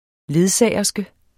Udtale [ ˈleðˌsæˀjʌsgə ]